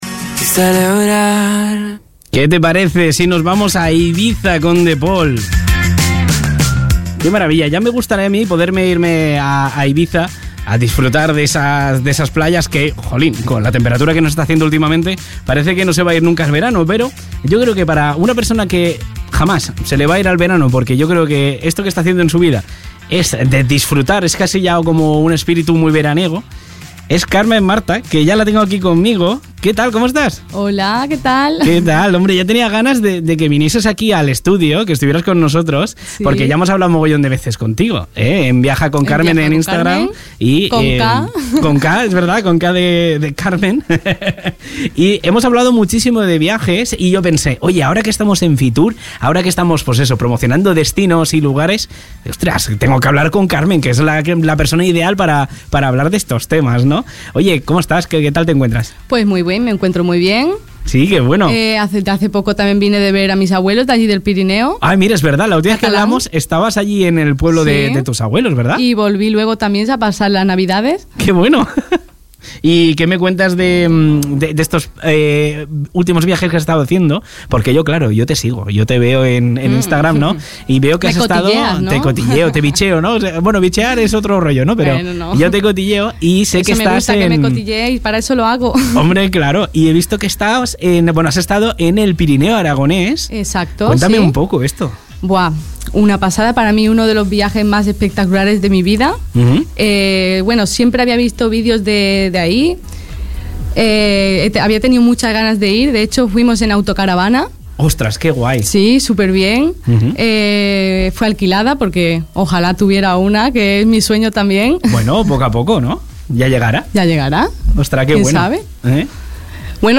Podcast de la entrevista completa: